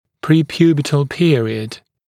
[ˌpriː’pjuːbətl ‘pɪərɪəd][ˌпри:’пйу:бэтл ‘пиэриэд]препубертатный период